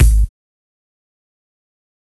Kick Groovin 5.wav